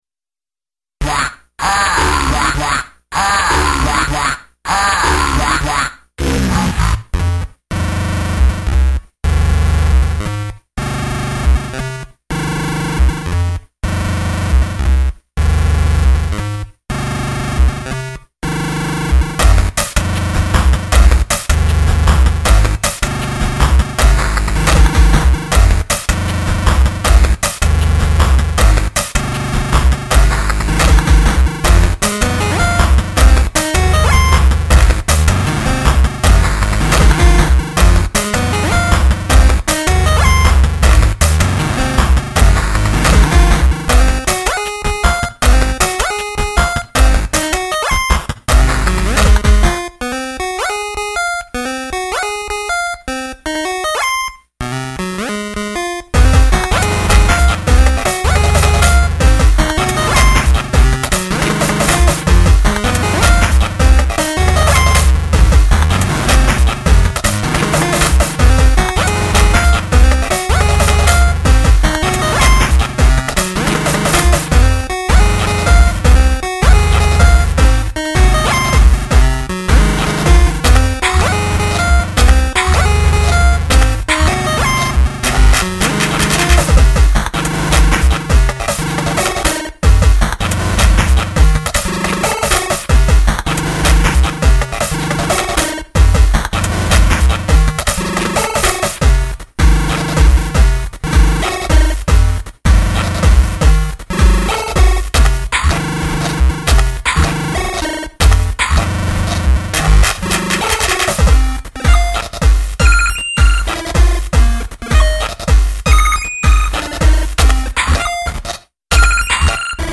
Played live